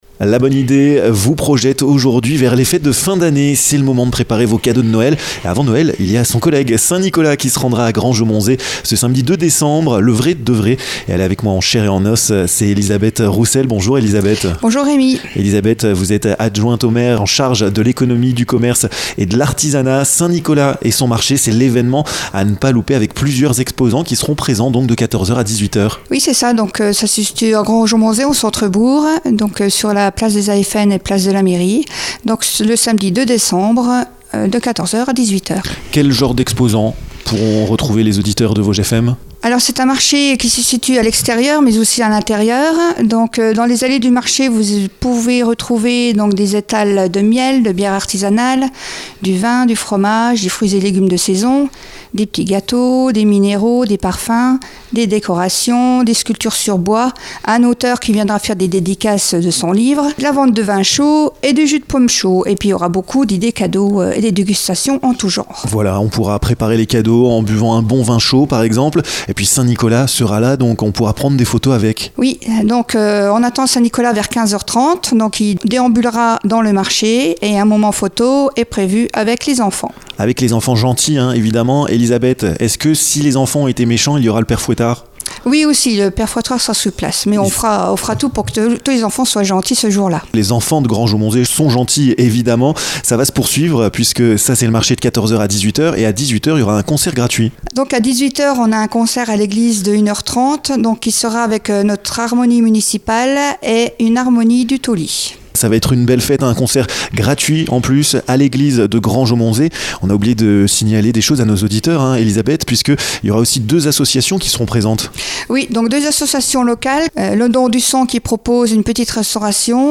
Elisabeth Roussel, adjointe au maire en charge de l'économie, du commerce et de l'artisanat, vous invite à ce bel événement!